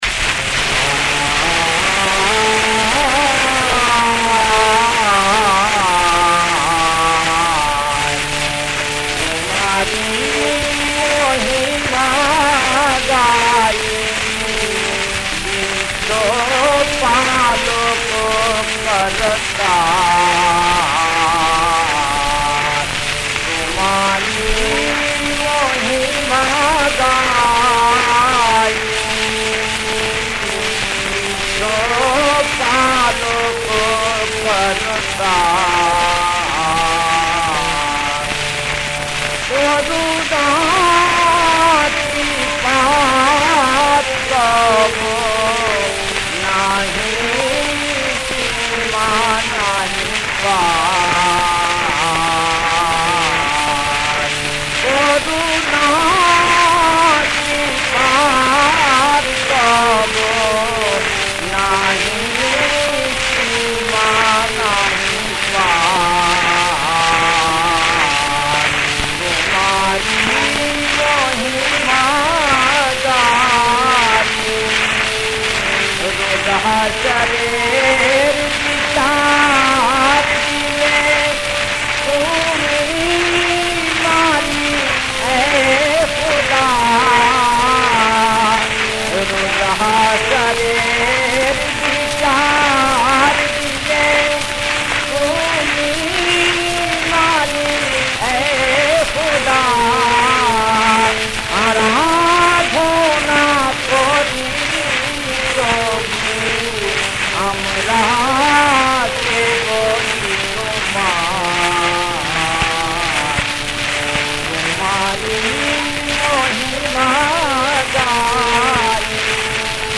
মান্দ-কার্ফা
• বিষয়াঙ্গ: ভক্তি (ইসলামী গান)
• তাল: তালবিহীন